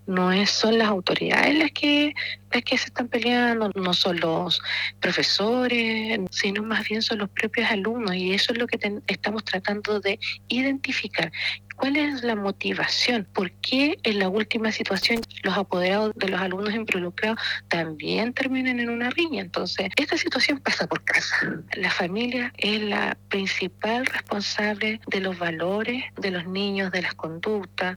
Por su parte, la alcaldesa de Arauco, Elizabeth Maricán, indicó que los liceos de la comuna son seguros, que no se debe generalizar y que han tomado medidas.
cuna-alcaldesa-arauco.mp3